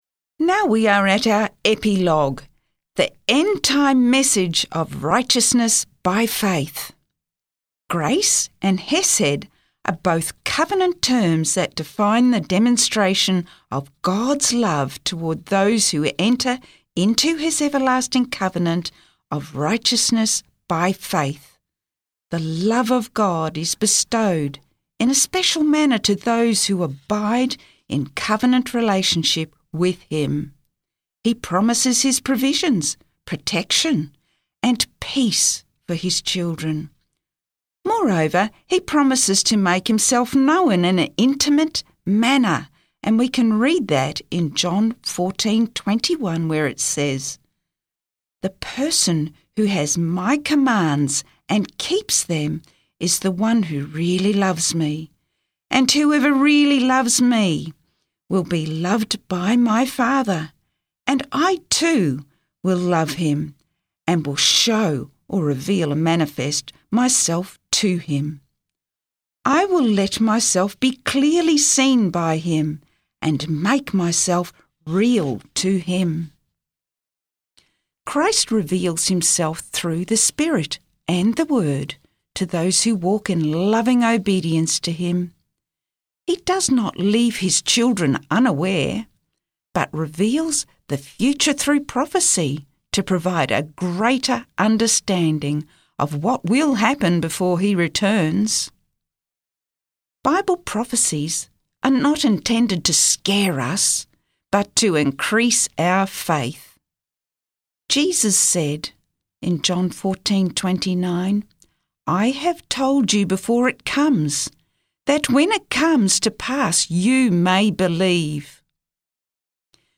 Book Reading